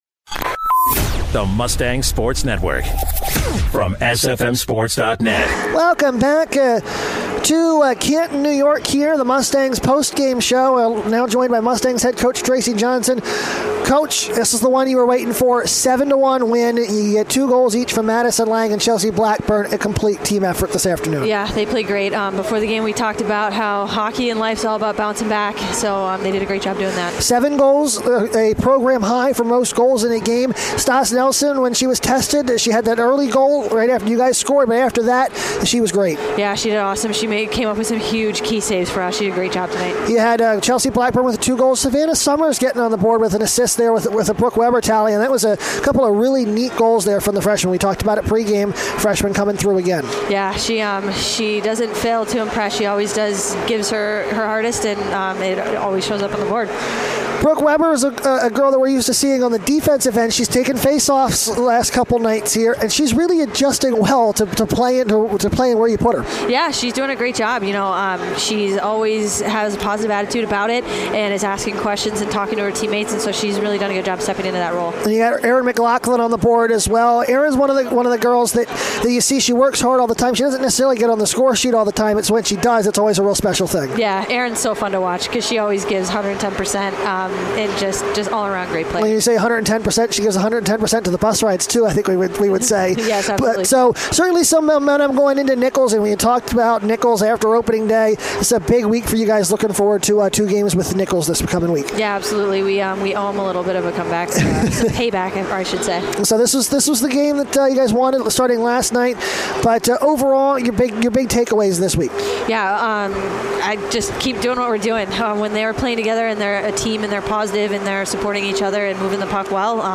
11/22/14: Stevenson Women's Ice Hockey Post Game Show